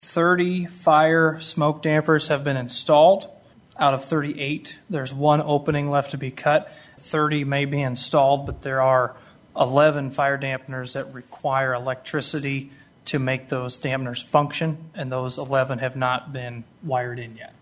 A PROGRESS REPORT AND DISCUSSION ON THE CONSTRUCTION OF THE NEW LOCAL LAW ENFORCEMENT CENTER WAS GIVEN TUESDAY AT THE WOODBURY COUNTY SUPERVISOR’S MEETING.
SUPERVISOR MARK NELSON, WHO ALSO SERVES ON THE JAIL AUTHORITY BOARD OVERSEEING THE PROJECT, TOLD HIS FELLOW SUPERVISORS THAT PROGRESS HAS BEEN MADE ON INSTALLING THE FIRE DAMPANERS IN THE BUILDING,WHICH HAVE CAUSED THE CONSTRUCTION DELAY IN THE PROJECT: